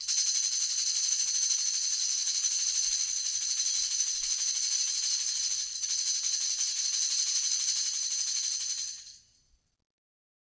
Tamb1-Roll_v2_rr1_Sum.wav